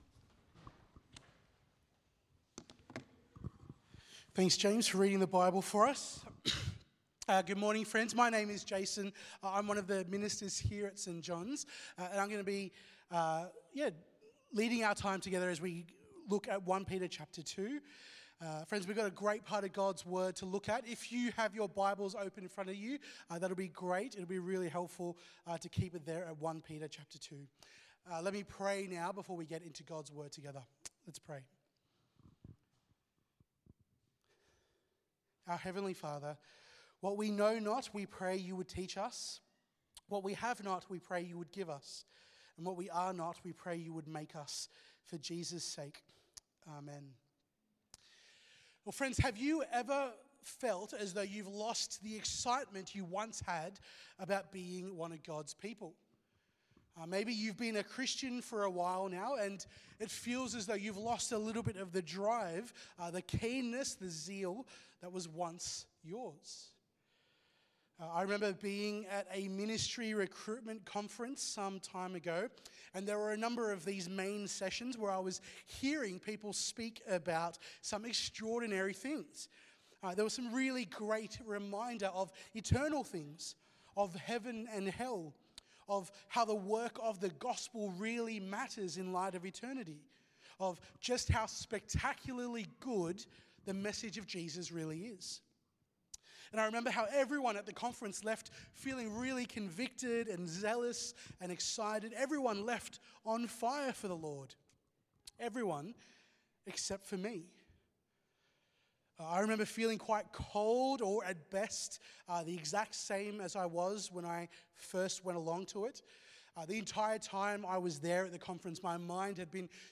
Sunday sermon
from St John’s Anglican Cathedral Parramatta.